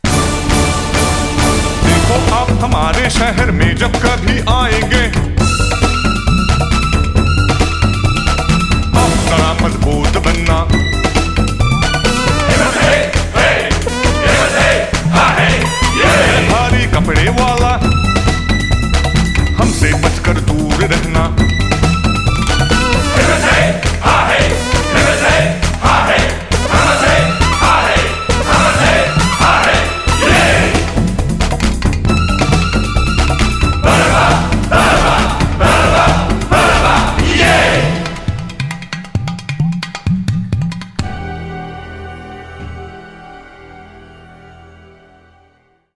Singer